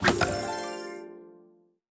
Media:ArcherQueen_evo2_dep.wav 部署音效 dep 在角色详情页面点击初级、经典、高手和顶尖形态选项卡触发的音效